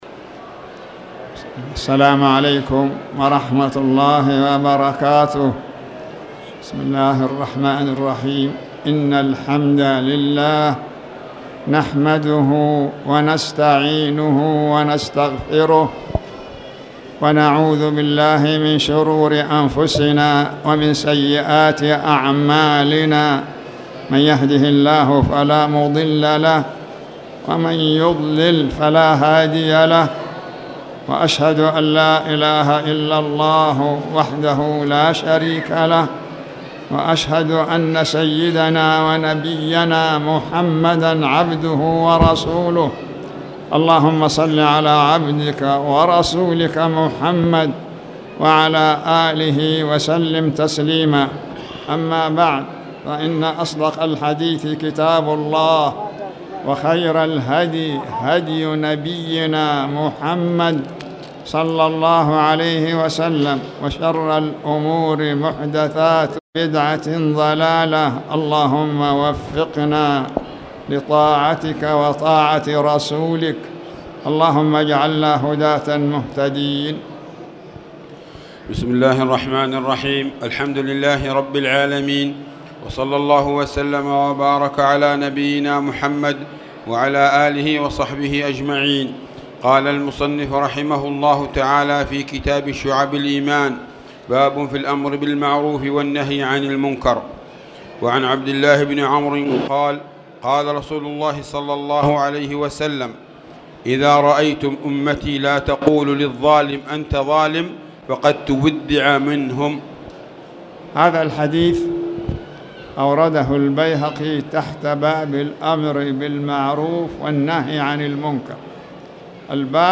تاريخ النشر ٣ جمادى الأولى ١٤٣٨ هـ المكان: المسجد الحرام الشيخ